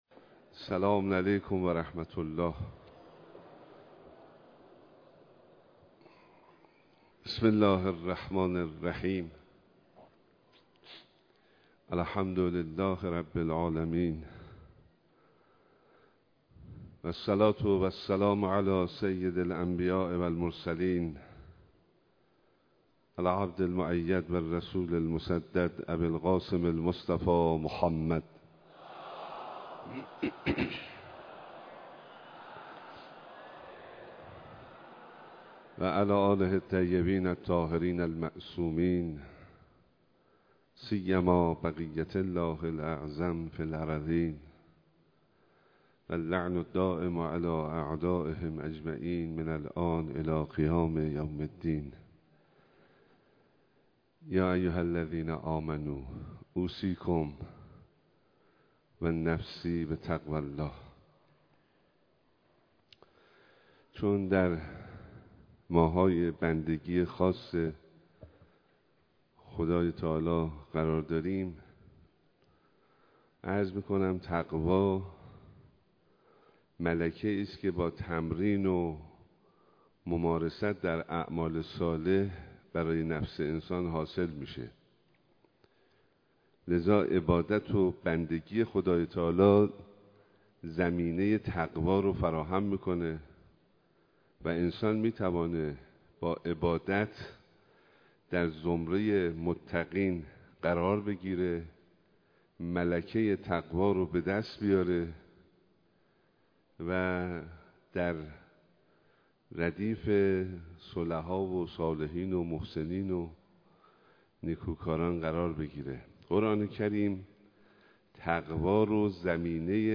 ایراد خطبه‌های نماز جمعه شهرستان کرج به امامت آیت‌الله حسینی همدانی نماینده ولی‌فقیه در استان البرز و امام‌جمعه کرج
صوت خطبه‌های نماز جمعه بیست و هفتم بهمن‌ماه شهرستان کرج
به گزارش روابط عمومی دفتر نماینده ولی‌فقیه در استان البرز و امام‌جمعه کرج، نماز جمعه بیست و هفتم بهمن‌ماه هزار و چهارصد و دو شهرستان کرج به امامت آیت‌الله حسینی همدانی در مصلای بزرگ امام خمینی (ره) برگزار شد.